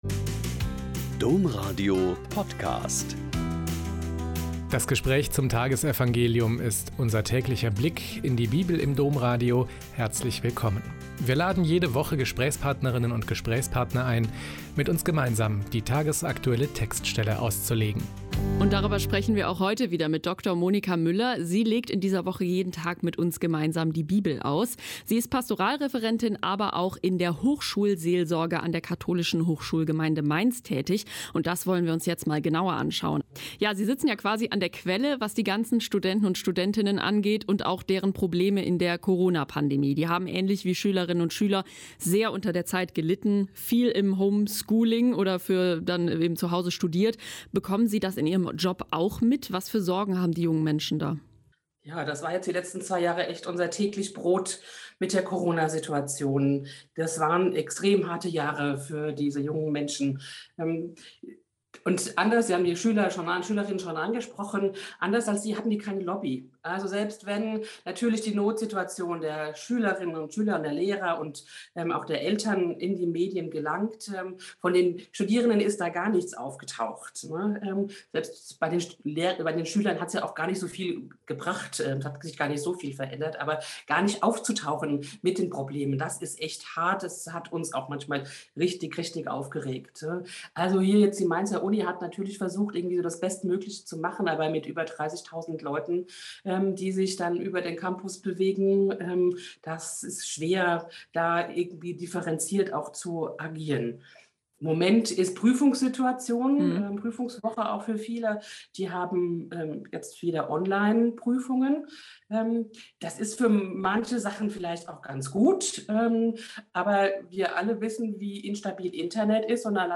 Mk 7,14-23 - Gespräch